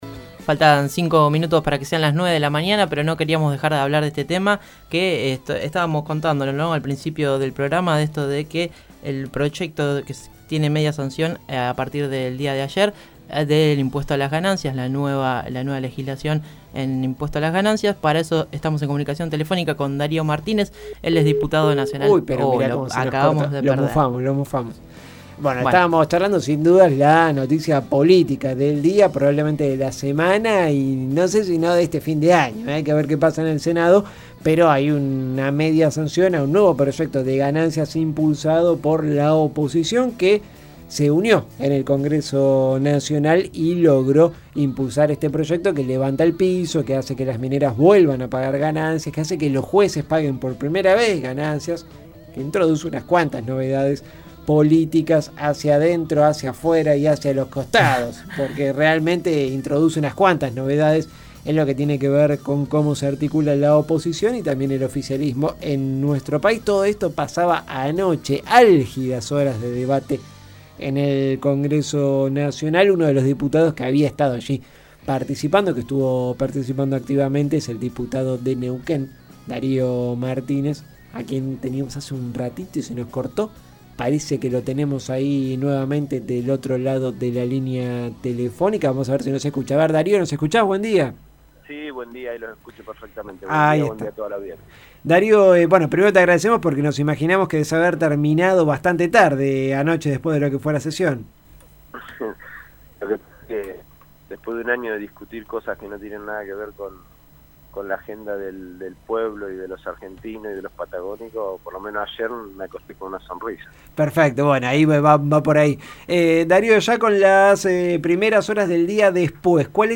(07/12/16) Caídos del Catre dialogó con el diputado nacional por Neuquén del Frente para la Victoria (FPV), Dario Martínez, acerca del proyecto de impuesto a las ganancias que se aprobó durante el día de ayer.